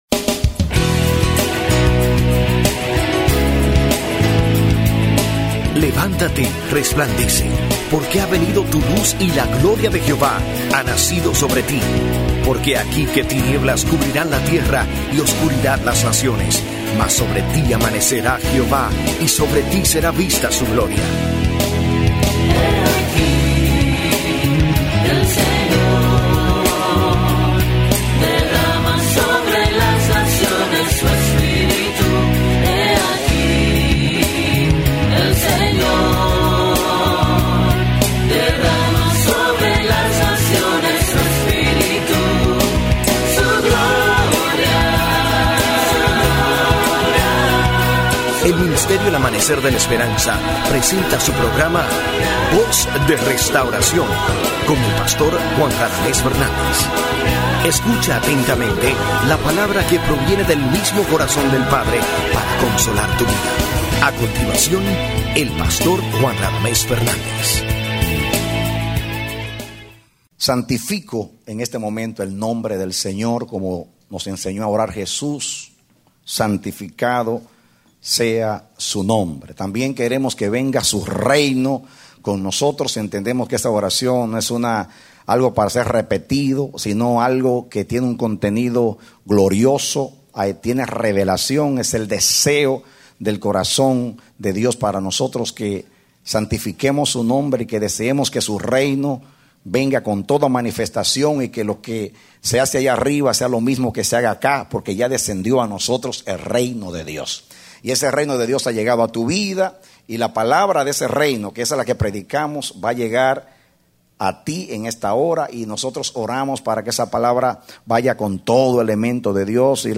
Predicado Agosto 2, 2009